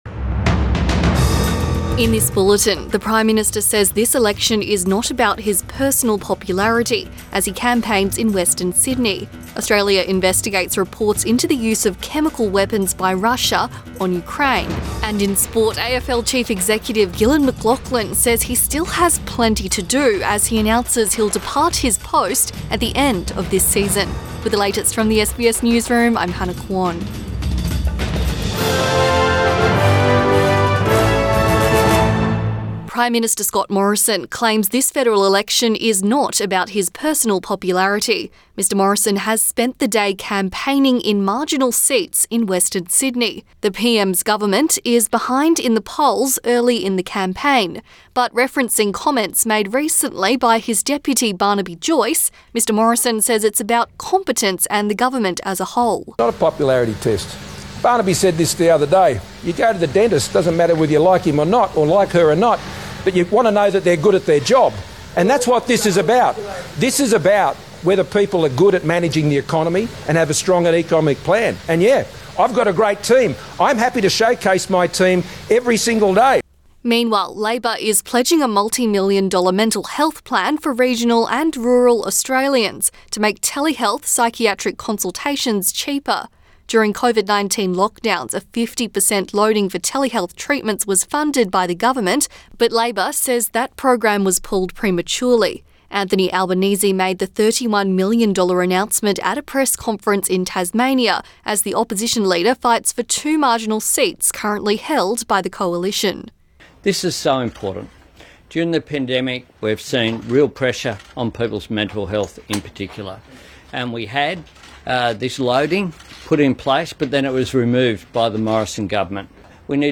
PM bulletin 12 April 2022